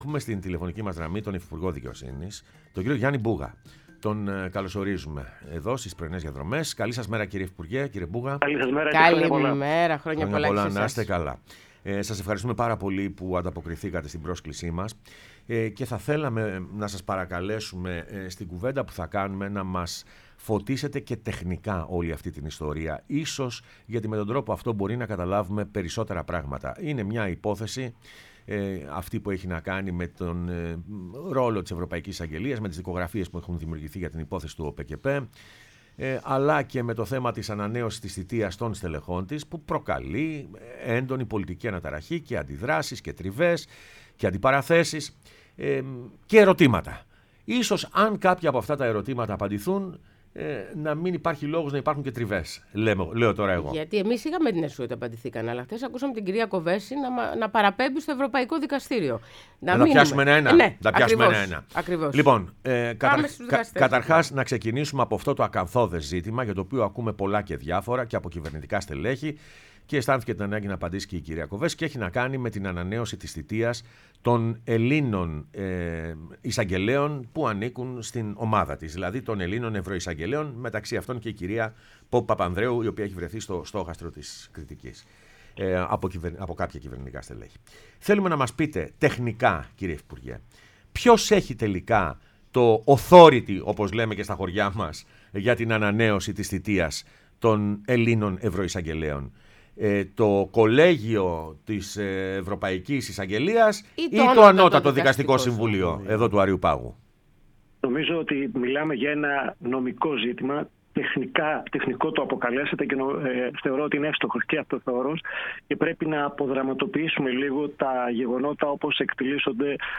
Ο Γιάννης Μπούγας, Υφυπουργός Δικαιοσύνης, μίλησε στην εκπομπή «Πρωινές Διαδρομές»